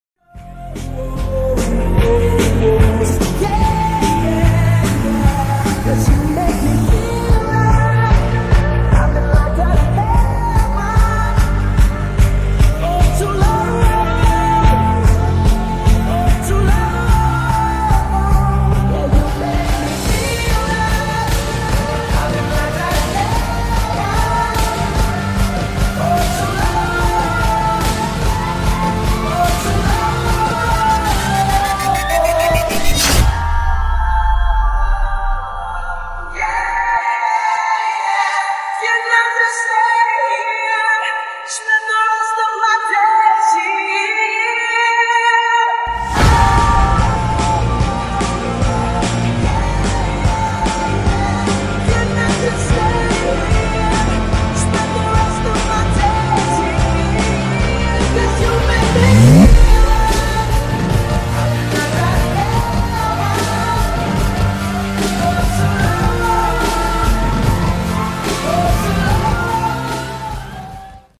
#8daudio